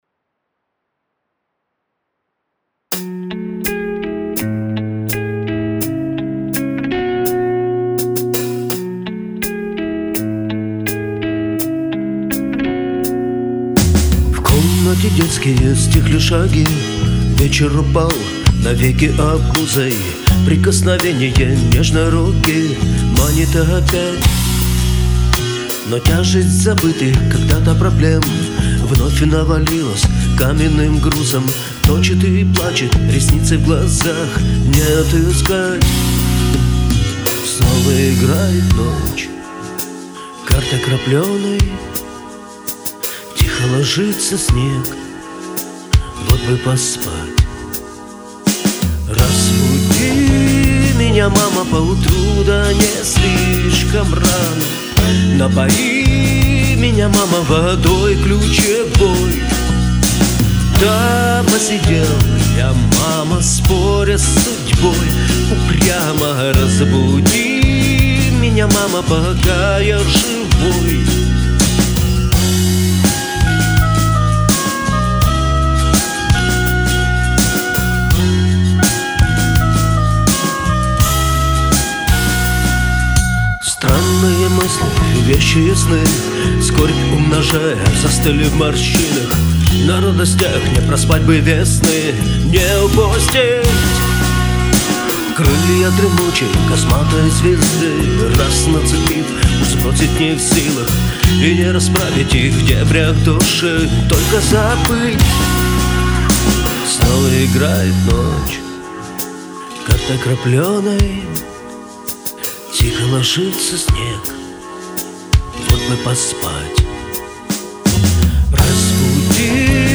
Новая песня. Записал ночью минус. С утра добавил гитару и спел. Как есть.